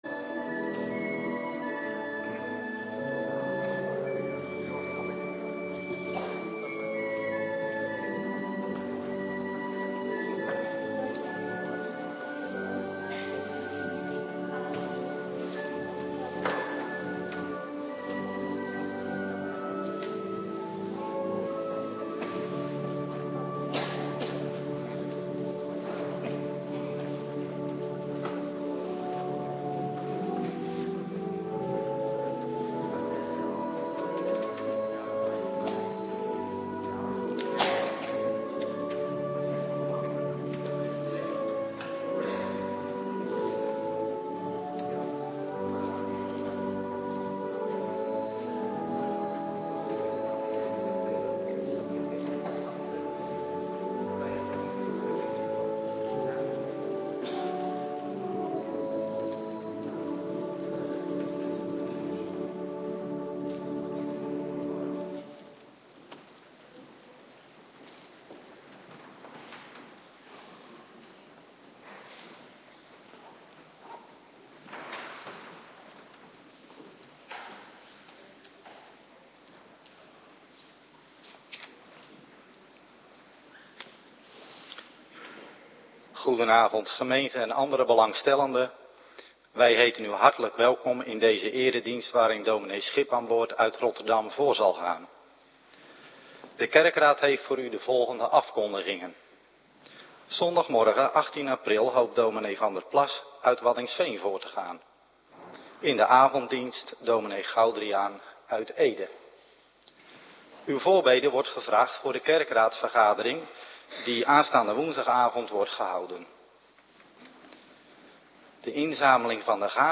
Avonddienst - Cluster 3
Locatie: Hervormde Gemeente Waarder